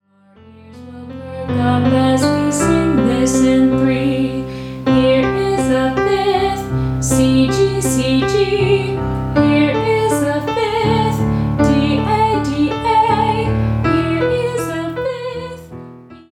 is a song in 3/4 time